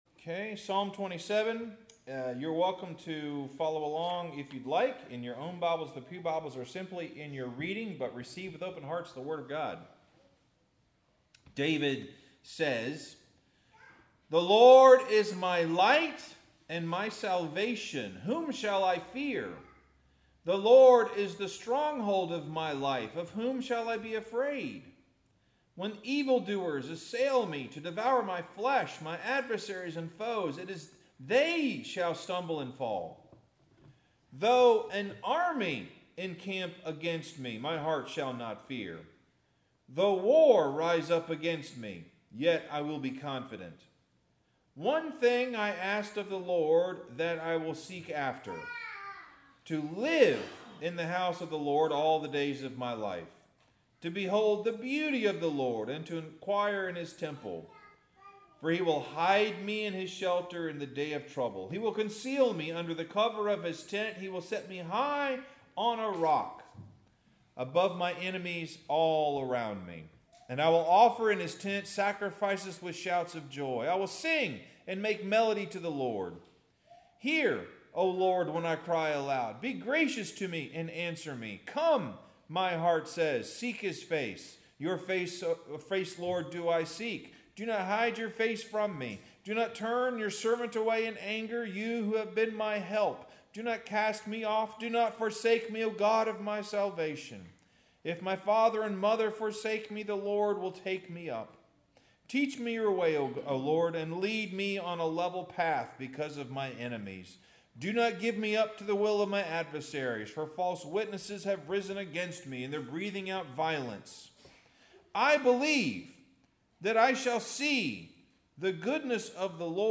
Sermon – A Moving Target Psalm 27; Luke 13:31-35 Farmville Presbyterian Church 3/16/25 There was a man golfing by himself out at the Wedgewood Golf Course this last week.